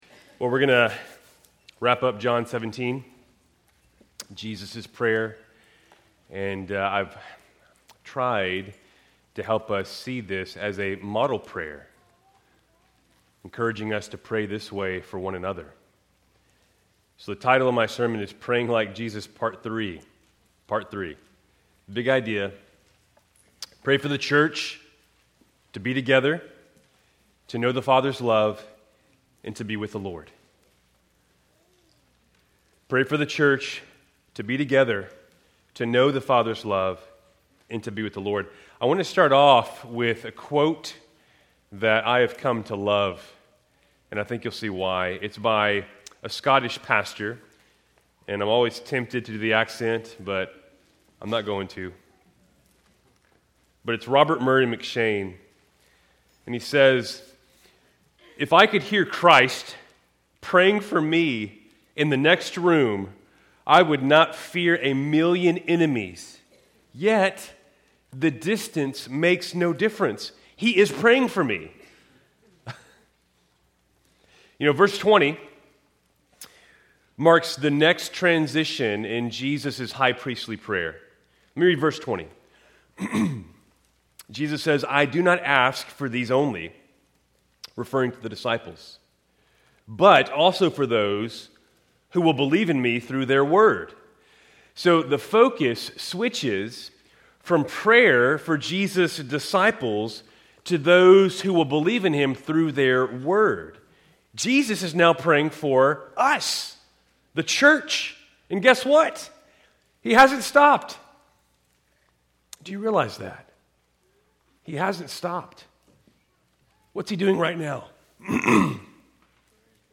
Keltys Worship Service, November 23, 2025